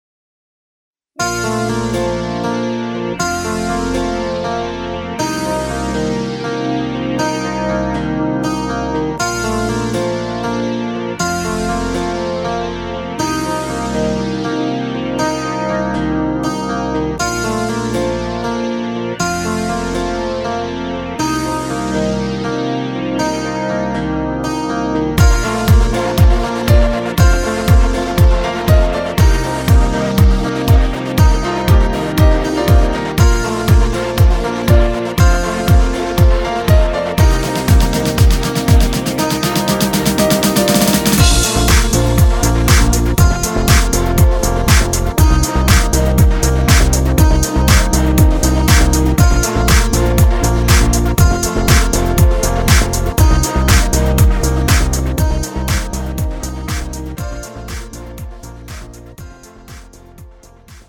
음정 (-1키)
장르 가요 구분 Premium MR